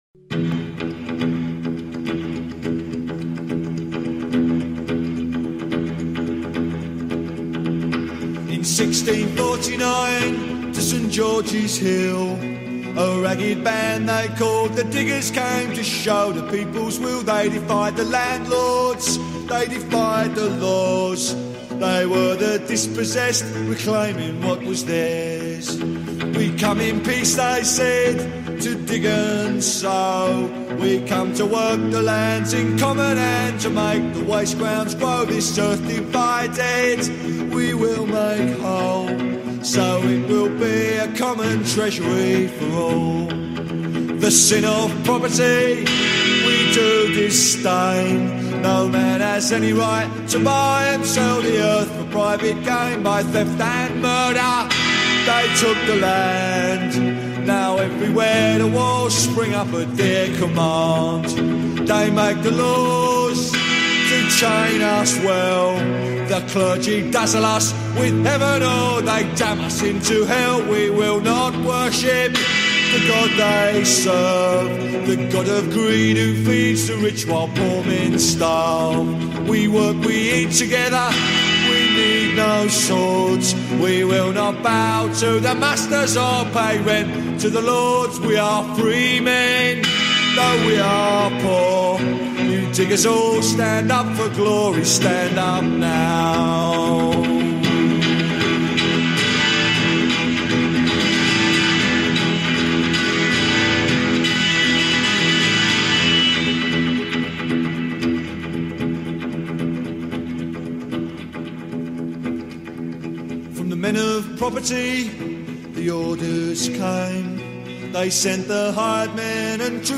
Talk Show Episode, Audio Podcast, Here We Stand and Carrying on our struggle to turn the world upside down on , show guests , about Carrying on our struggle to turn the world upside down, categorized as History,News,Politics & Government,Religion,Society and Culture